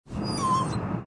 Play, download and share perro gime original sound button!!!!
perro-gime.mp3